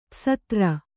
♪ tsatra